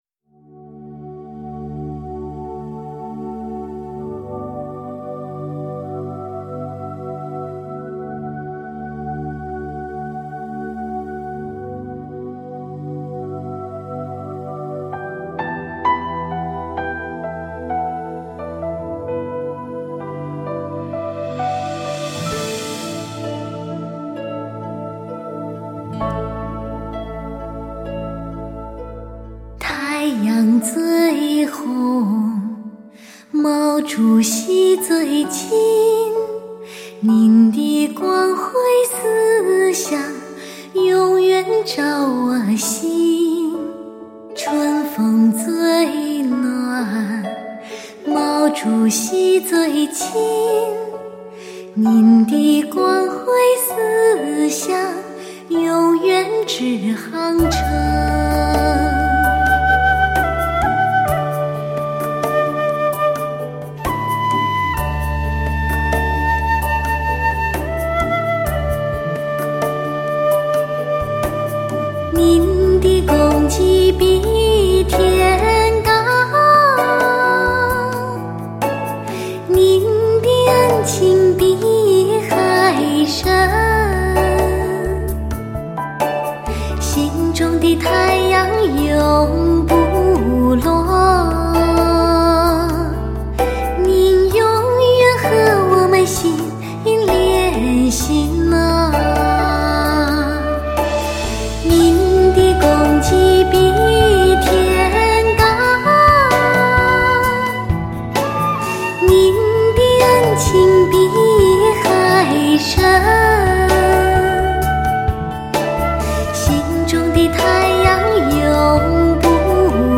爱国经典重温，澎湃的力量穿越时代，极具浓郁质朴的自然民韵之声，身临奇境的音色，享受、传承最具典藏价值的天籁。
感动千秋万代的浓情热血，重回那个热火朝天的燃情岁月，声声响亮动人，耳熟能详的旋律演绎发烧HIFI精品。